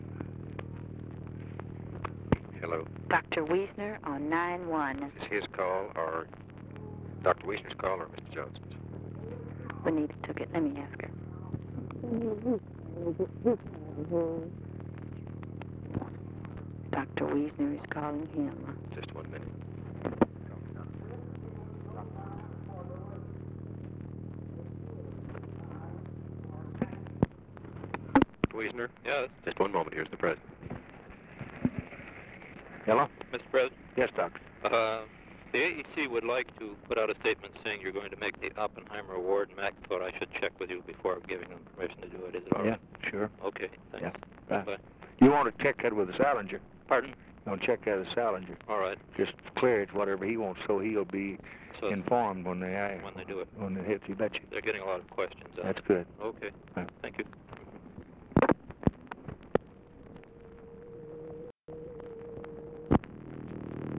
Conversation with JEROME WEISNER, November 27, 1963
Secret White House Tapes